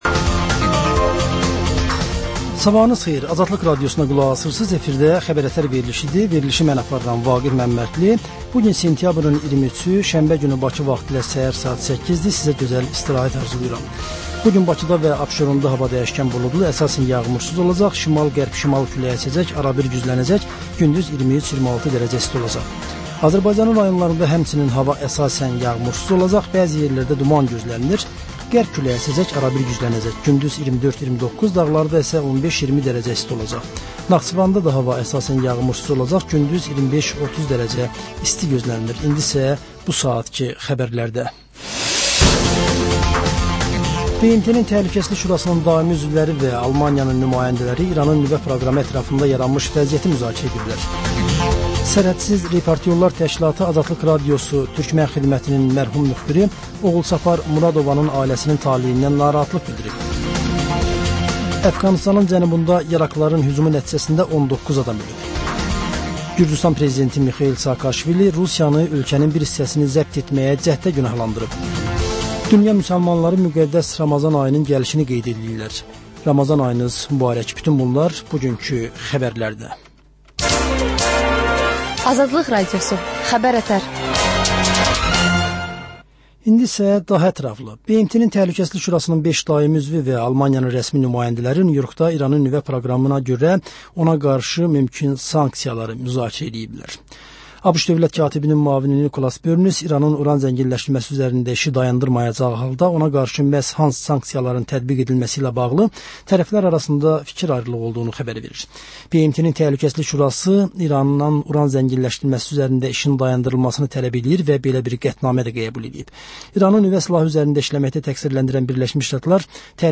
S əhər-səhər, Xəbər-ətər: xəbərlər, reportajlar, müsahibələrVə: Canlı efirdə dəyirmi masa söhbətinin təkrarı.